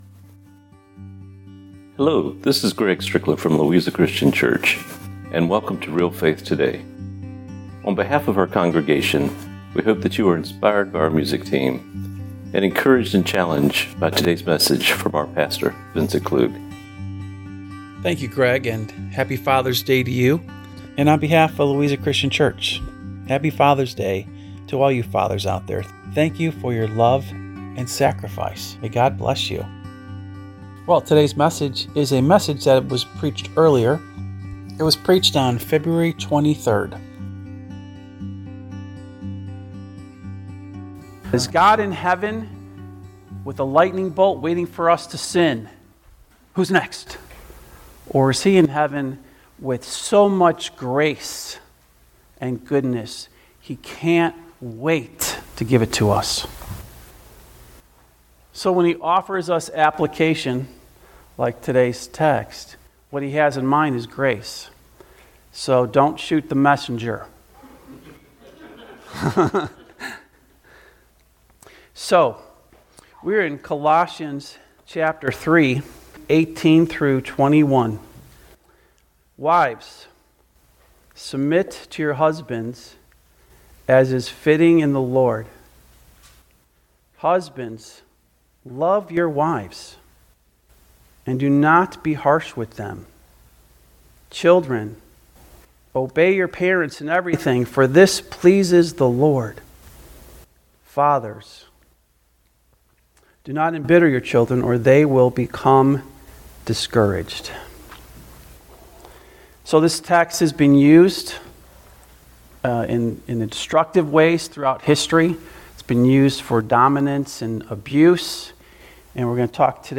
Radio-Broadcast-The-Submission-Mission-with-FAthers-Day.mp3